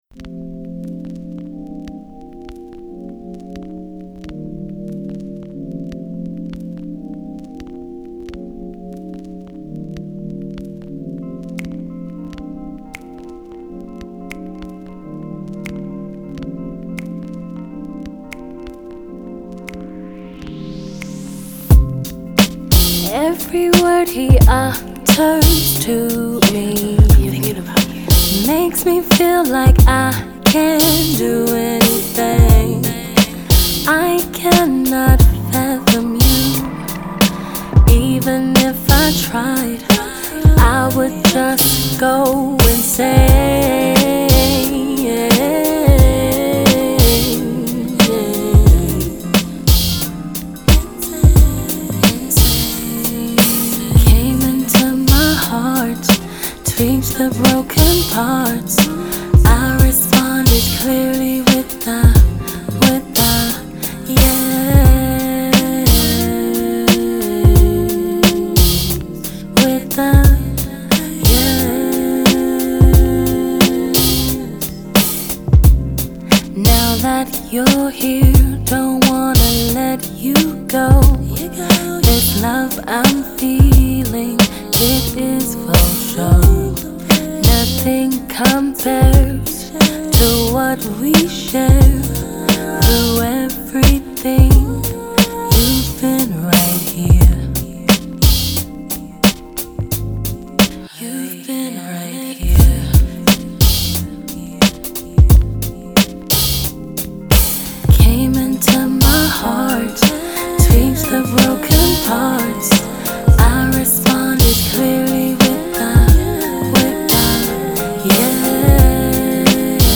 a Nigerian singer/songwriter based in the UK.
smooth and seamless singing